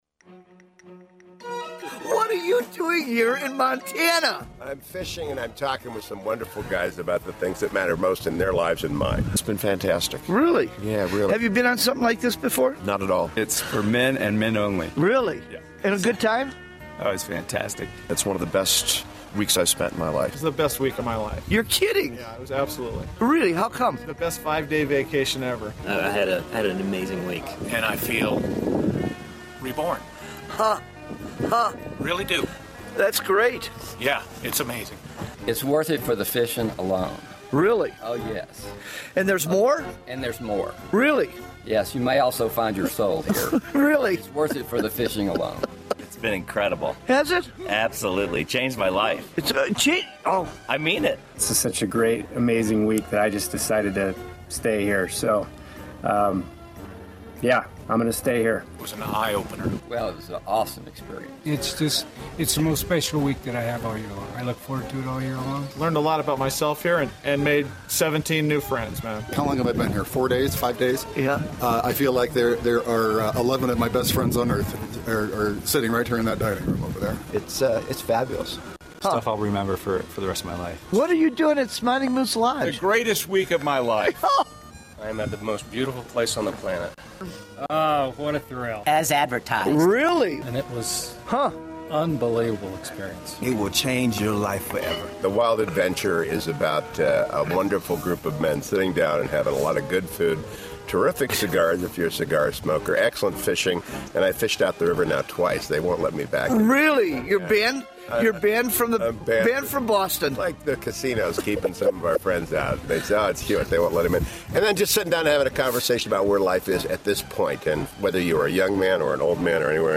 Ask your friend to listen to TWA testimonials
TheWildAdventure-Testimonials2016.mp3